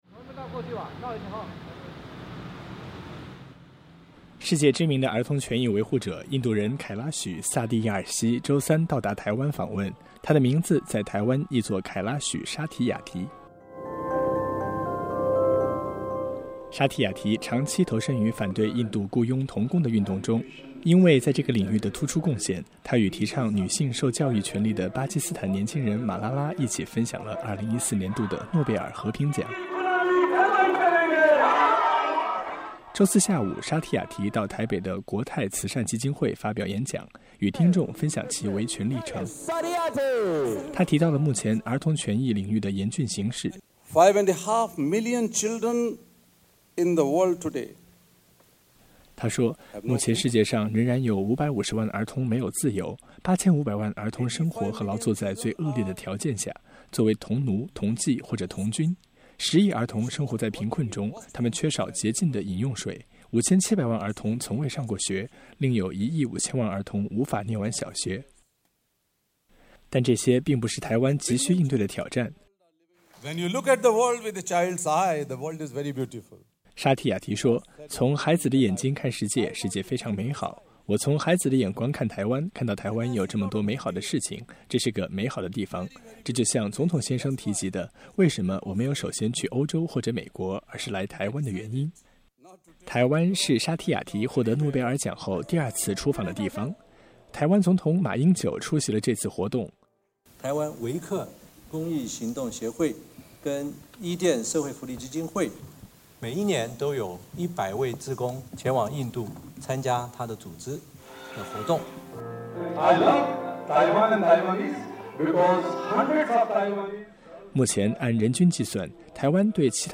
周四下午沙提雅提到台北的“国泰慈善基金会”发表演讲，与听众分享其维权历程，他提到了目前儿童权益领域的严峻形势。
台湾是沙提雅提获得诺贝尔奖后第二次离开印度出访，台湾总统马英九出席了这次活动。